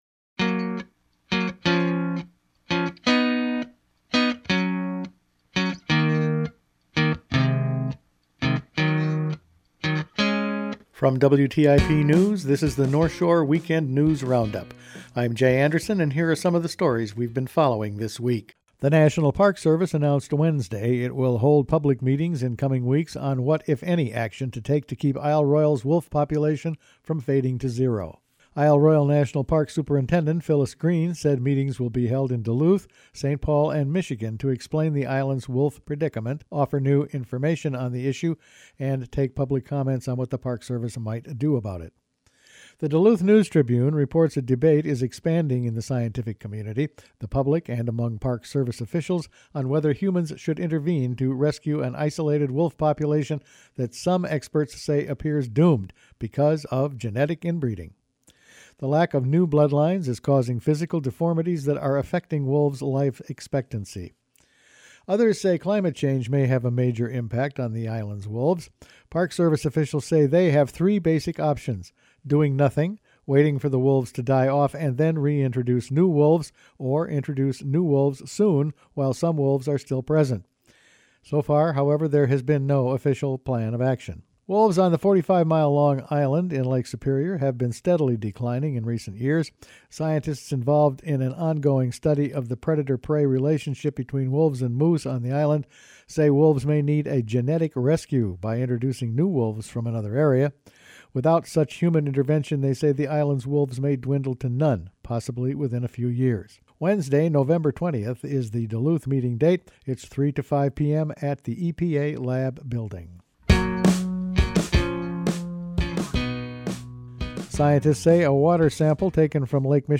Weekend News Roundup for November 9
Each week the WTIP news staff puts together a roundup of the news over the past five days. Wolves on Isle Royale, Carp in the Great Lakes, Mines in Minnesota and Wisconsin, gypsy moths in the Arrowhead and good news on 1% tax collections…all in this week’s news.